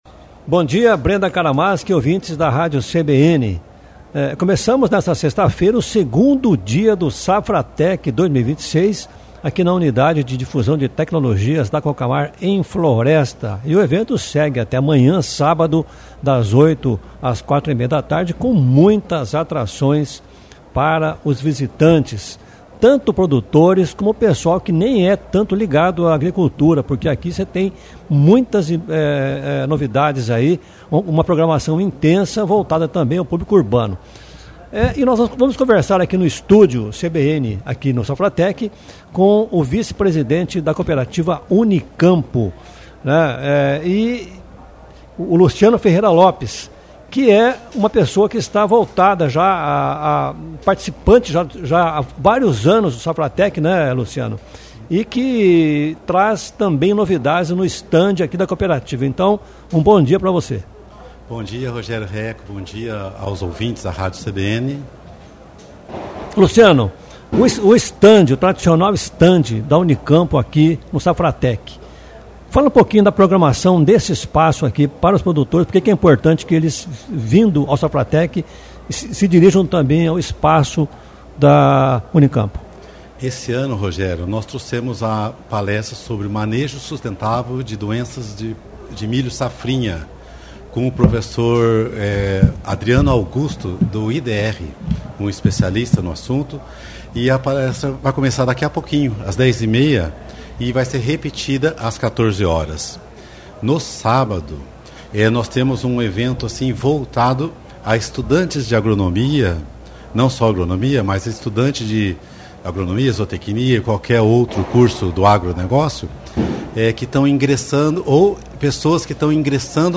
A entrevista foi realizada no estúdio móvel da CBN, instalado na Unidade de Difusão Tecnológica da Cocamar (UDT), em Floresta, onde ocorre a edição 2026 do Safratec.